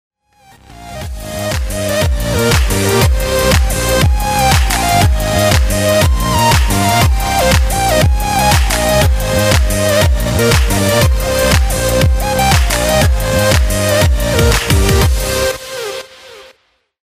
آهنگ موبایل شاد و ریتمیک